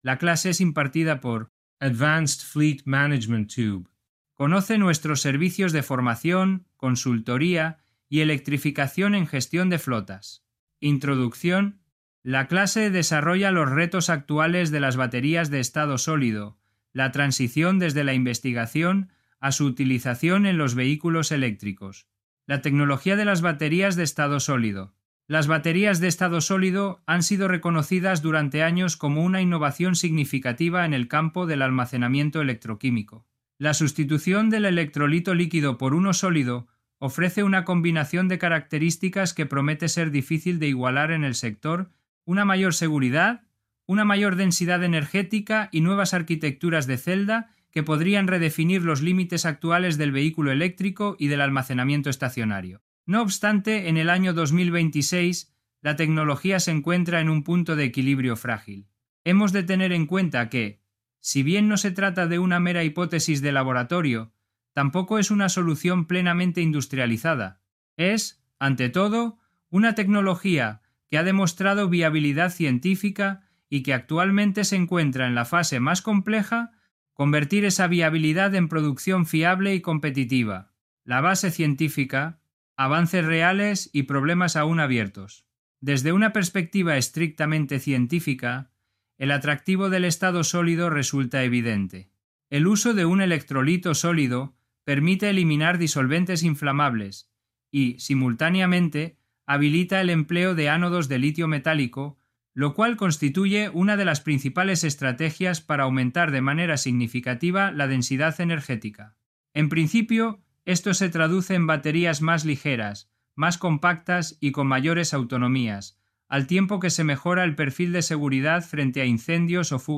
La clase desarrolla los retos actuales de las baterías de estado sólido, la transición desde la investigación a su utilización en los vehículos eléctricos.